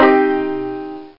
Banjo Min Sound Effect
banjo-min.mp3